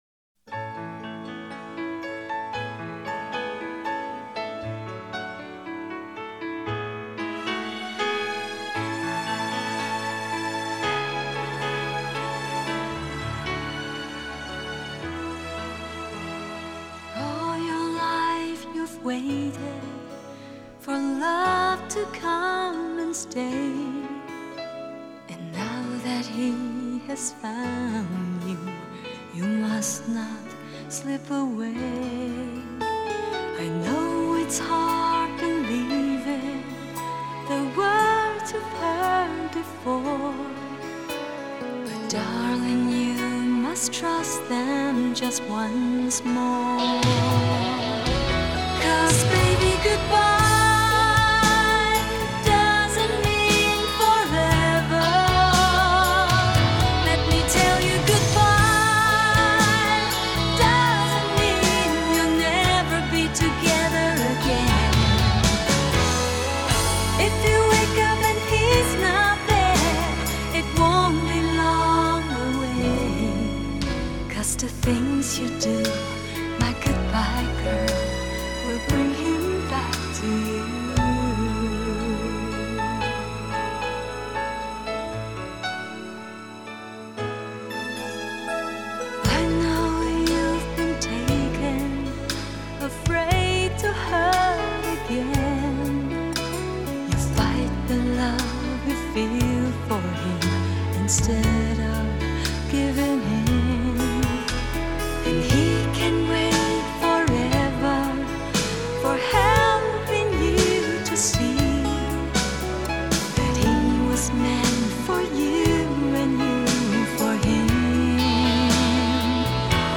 ☆专业24bit混音母带处理，原音高品质重现
优雅的英文咬字，伴随张力十足的情感拿捏与澎湃感人的唱功，总是让人无法自拔地如痴如醉。
★ 爱的试听 Wma,VBR,54k ★